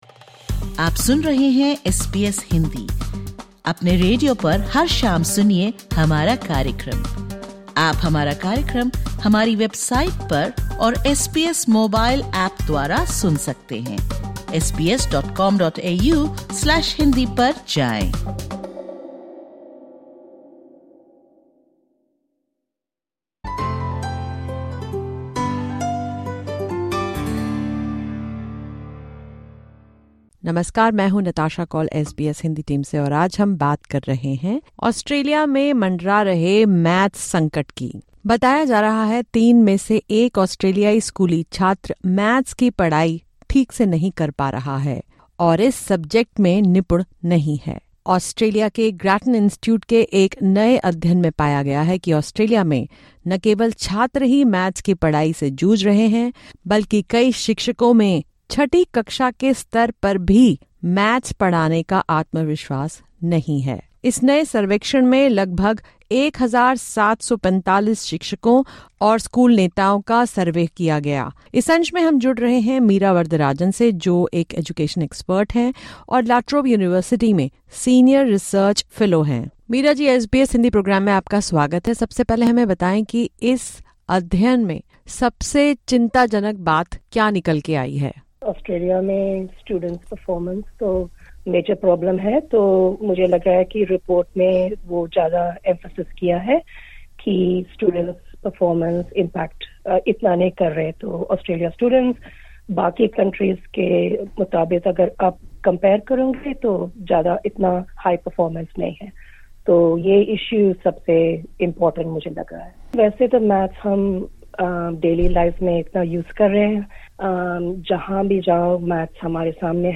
we chat with education researcher